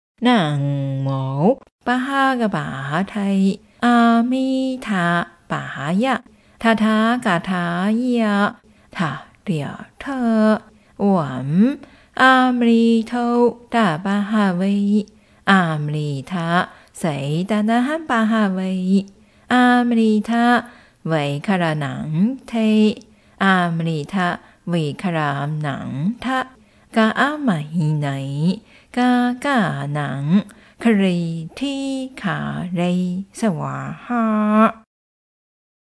( 悉曇古音往生短咒 )